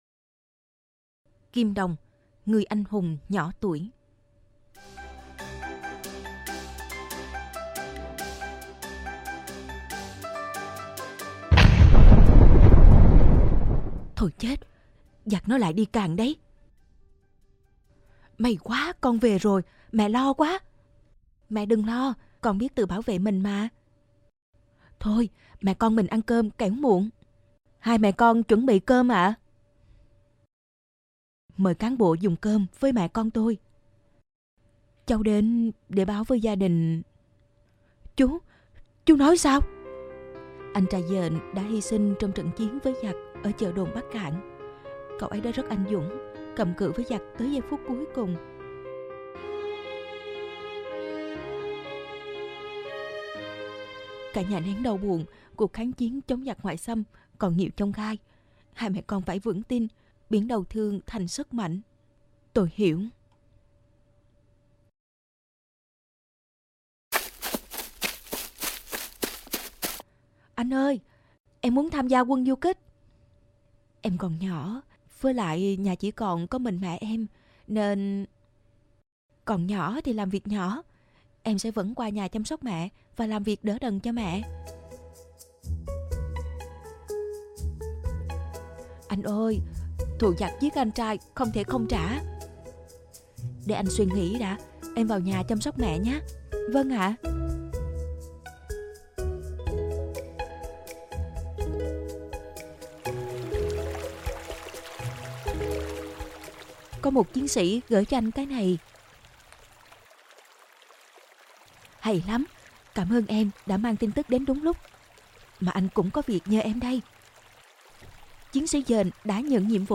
Sách nói | Kim Đồng - Người anh hùng nhỏ tuổi